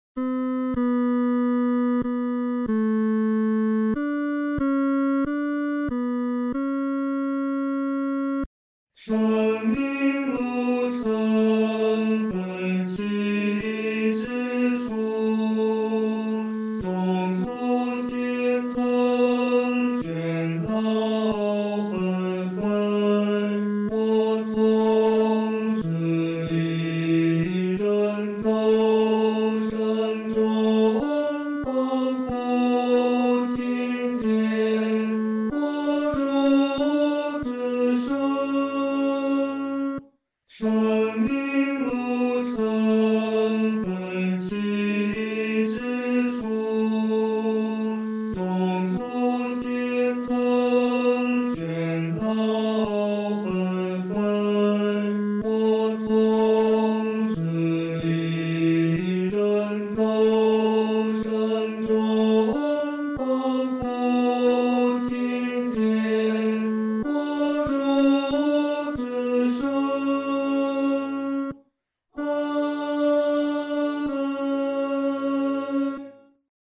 合唱
男高
这首圣诗适用中速弹唱